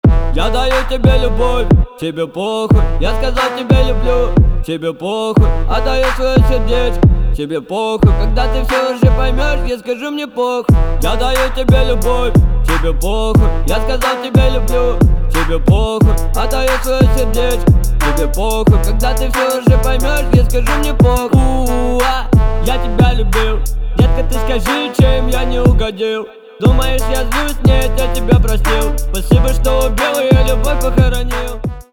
Жанр: Русский рэп / Русские